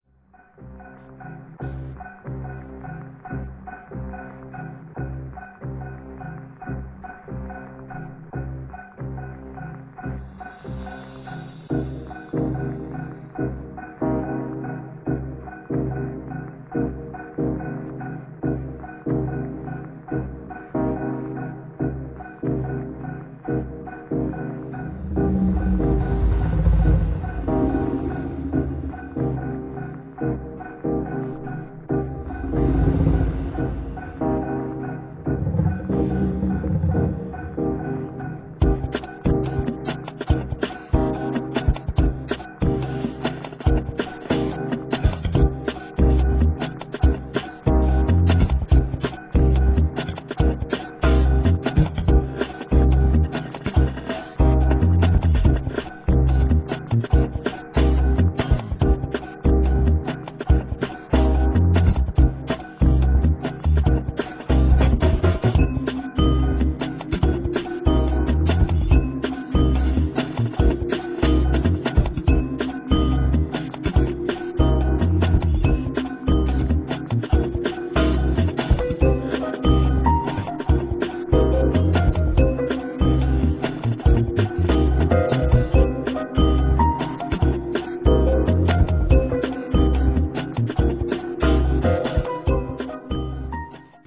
der inbegriff von nujazz